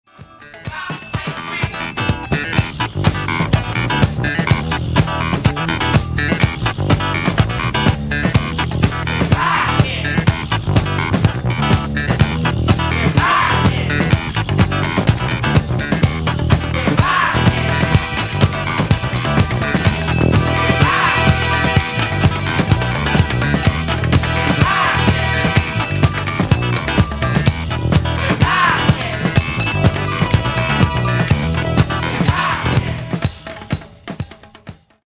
Genere:   Disco | Funk | Soul